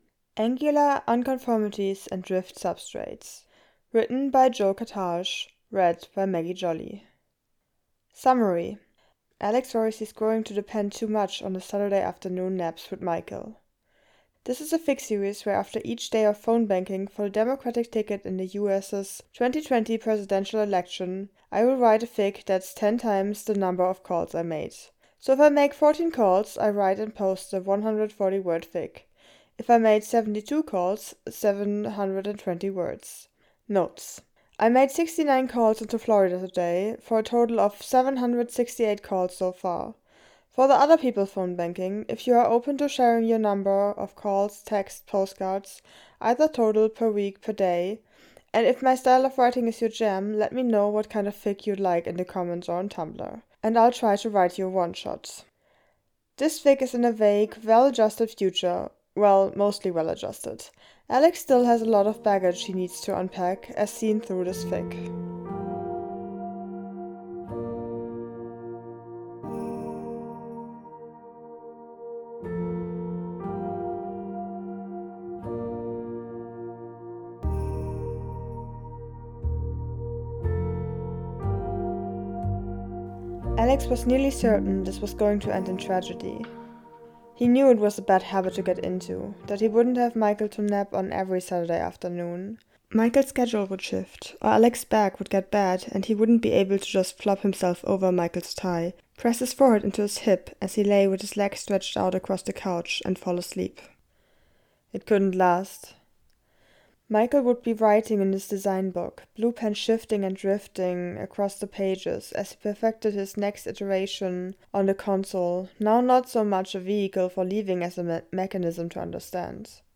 A podfic interspersed with songs